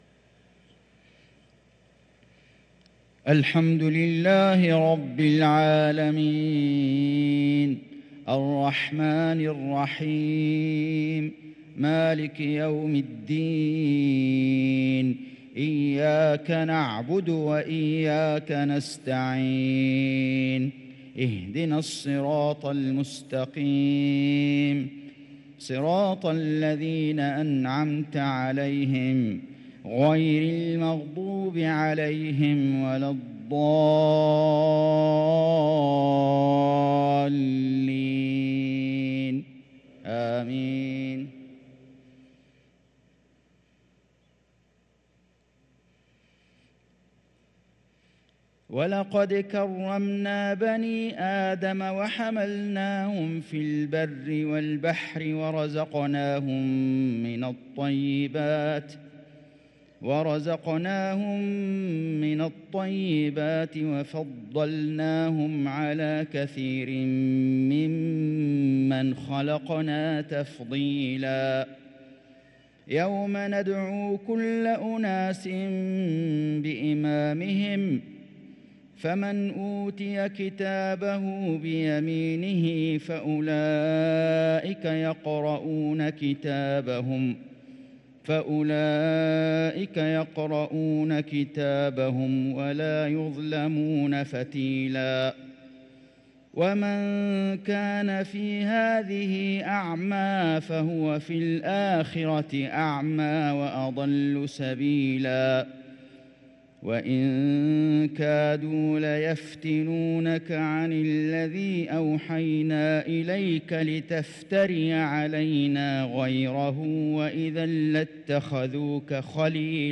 صلاة العشاء للقارئ فيصل غزاوي 5 ربيع الأول 1444 هـ
تِلَاوَات الْحَرَمَيْن .